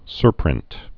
(sûrprĭnt)